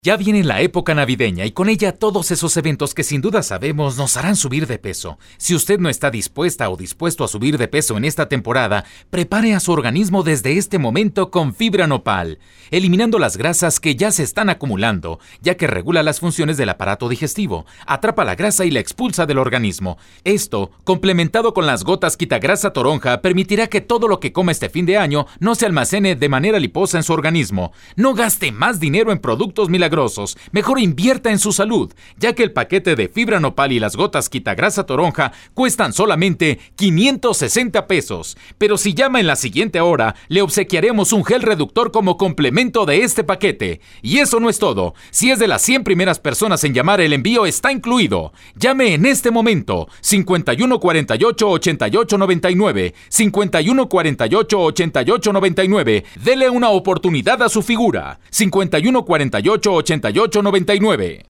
• RADIO Y TV
Demo anuncio en radio